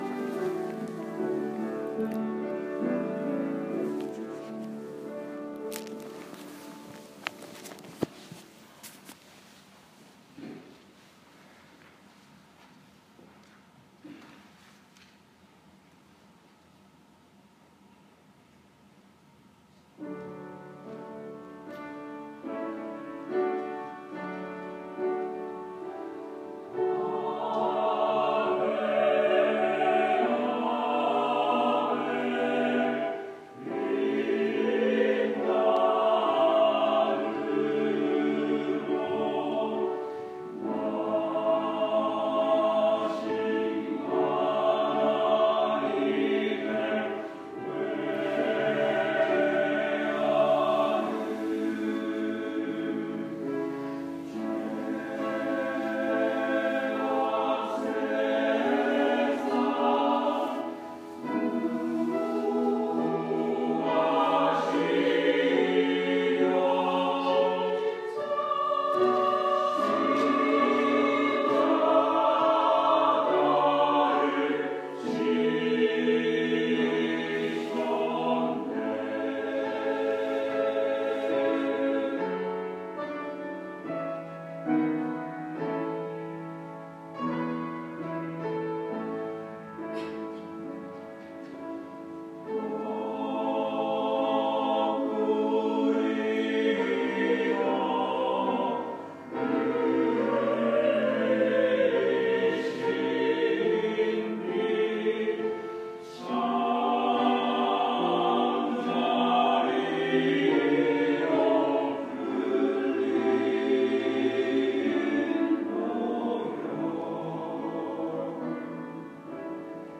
2월 21일 주일 찬양대(존귀하신 주, 모짜르트)